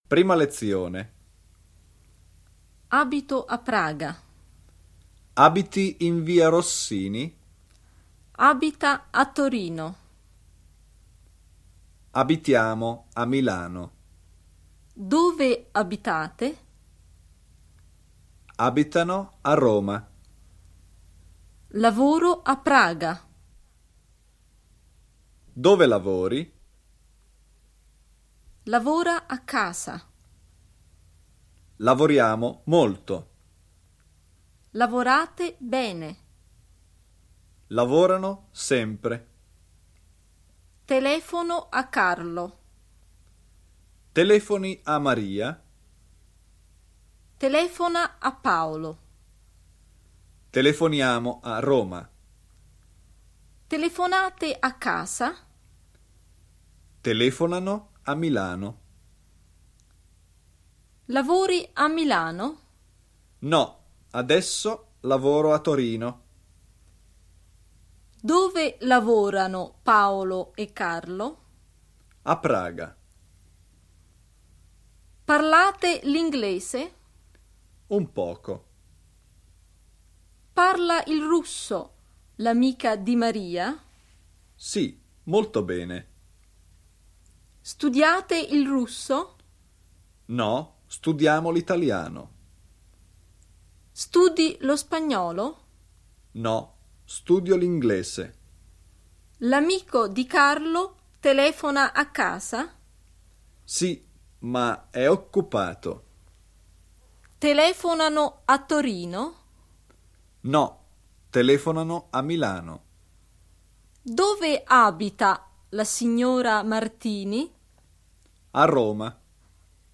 Italština pro samouky audiokniha
Ukázka z knihy